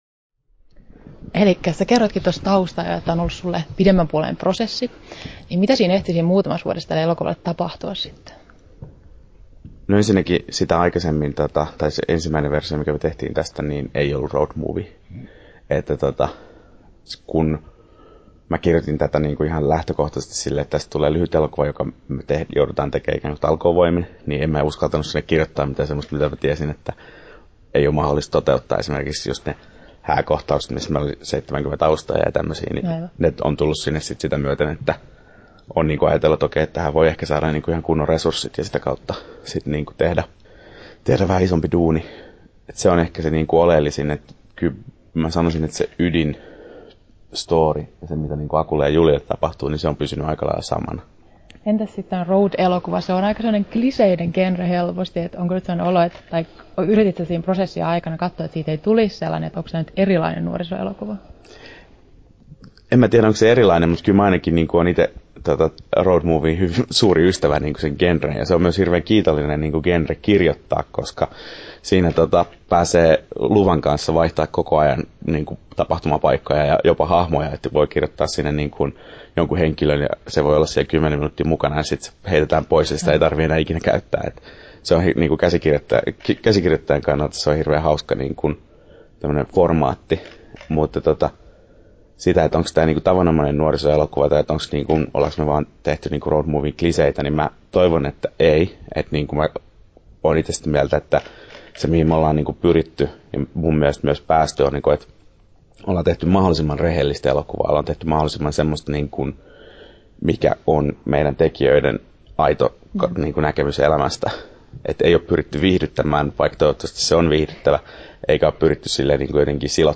haastattelu
8'38" Tallennettu: 28.3.2011, Turku Toimittaja